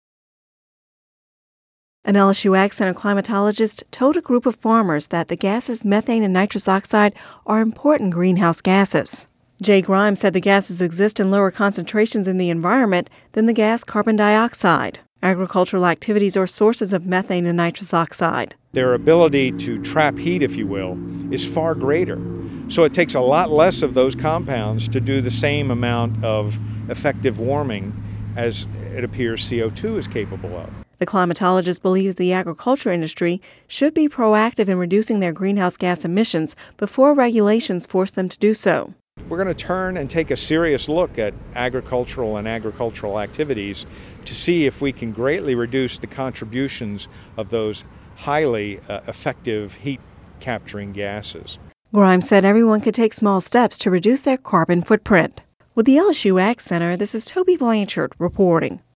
(Radio News 02/01/10) Agricultural activities are sources of methane and nitrous oxide.